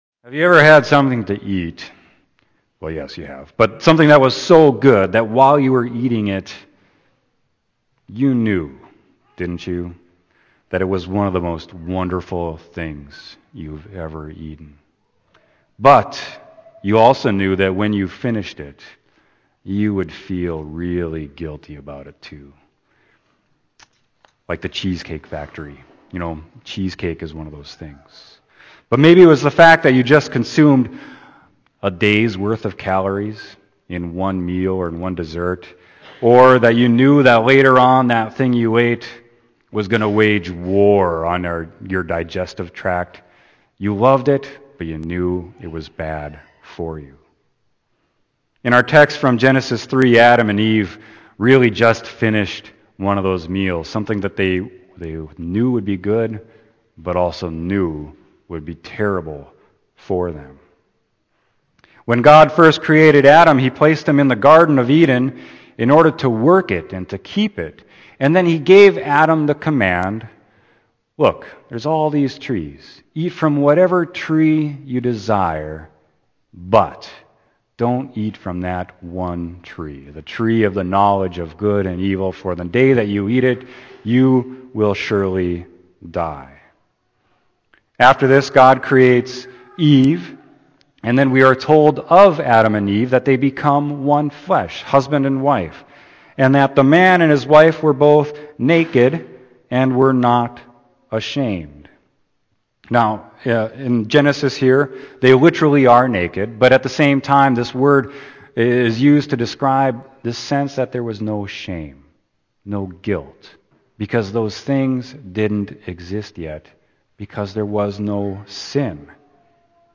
Sermon: Genesis 3.8-15